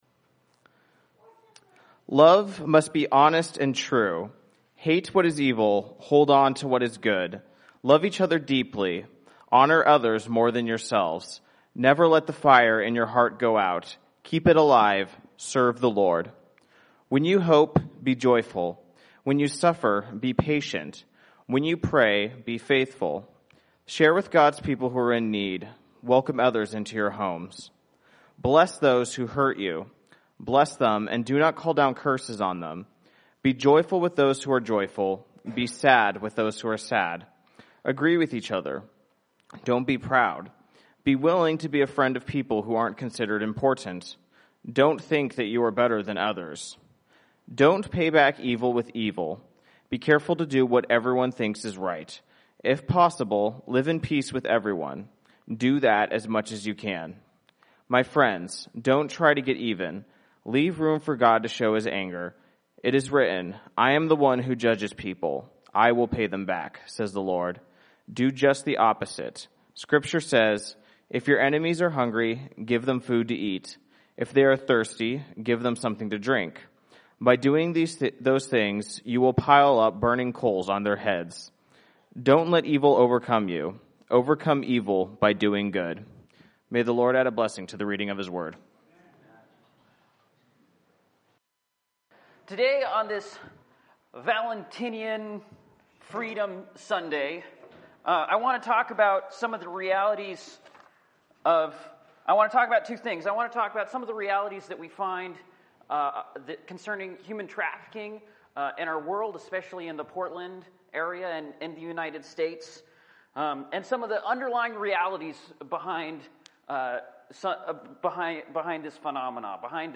Bible Text: Romans 12:9-21 | Preacher